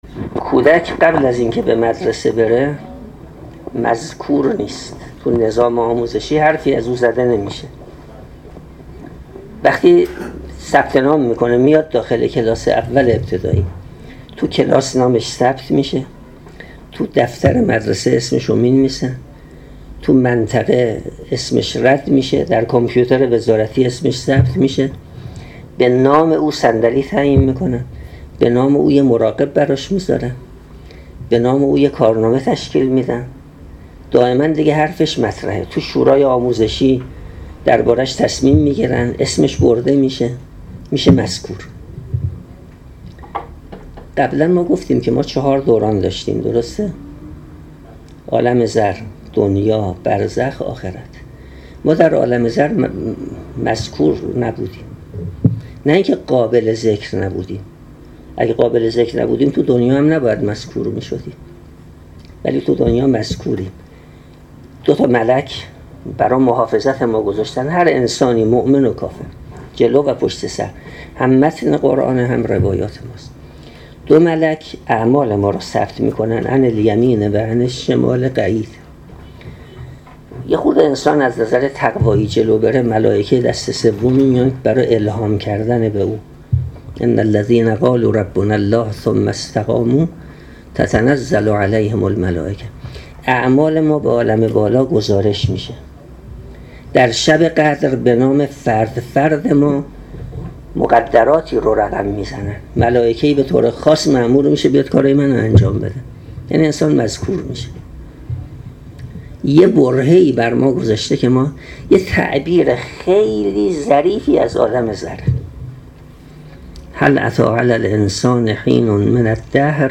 چهارمین جلسه دوره آموزشی تدبر در قرآن کریم